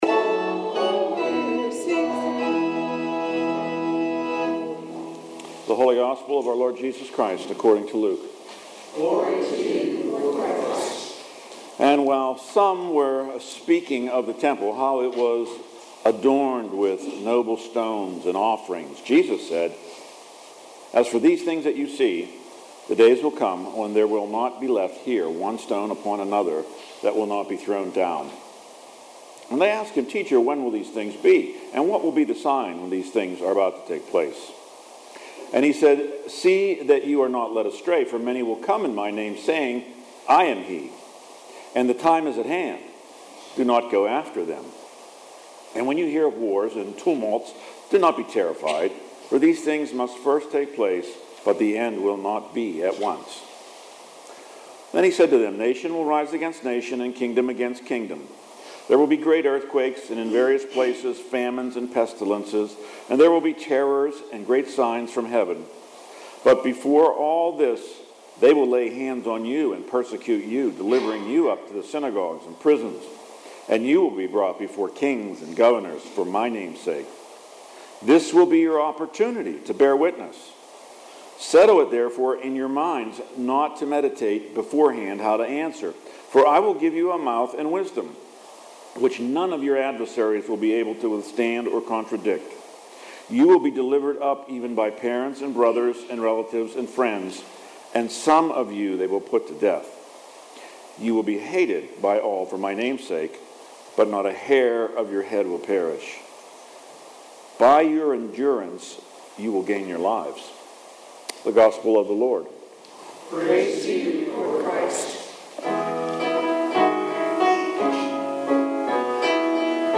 Readings and Sermon November 16 – Saint Alban's Anglican Church